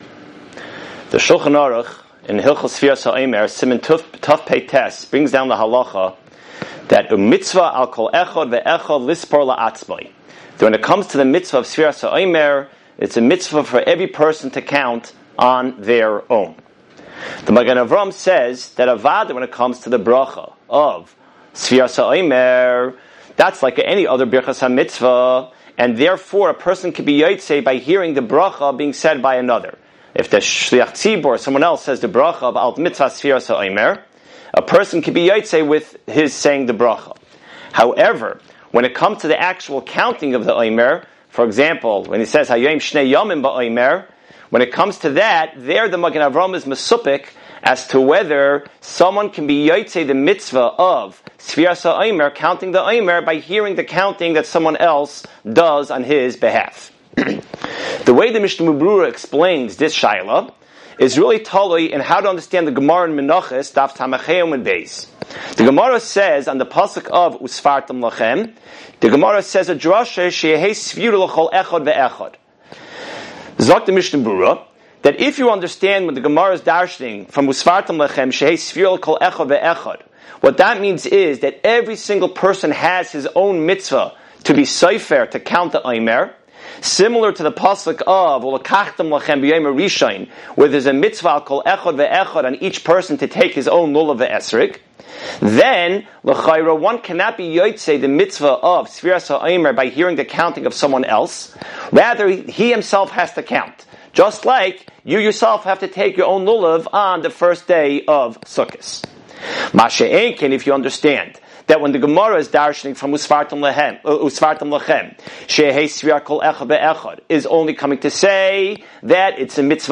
Weekly Alumni Shiur - Ner Israel Rabbinical College